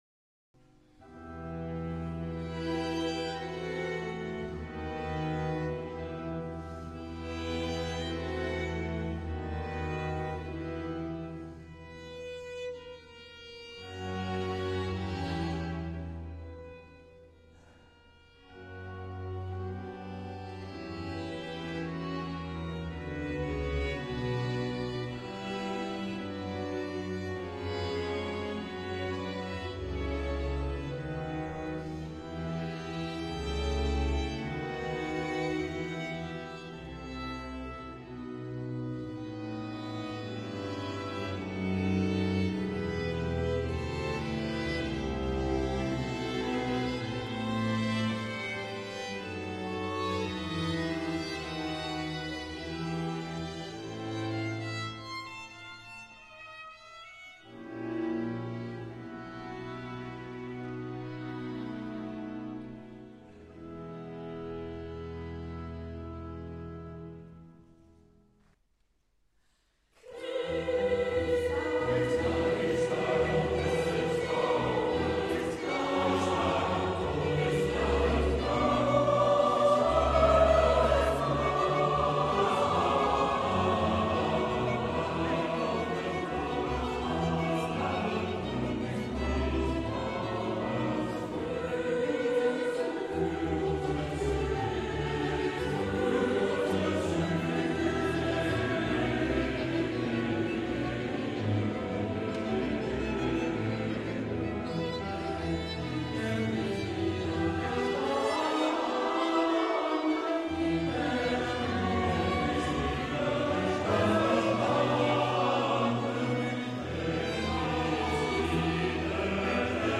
donnée à Porrentruy le 23 juin 2012
Sinfonia + Verset 1 chœur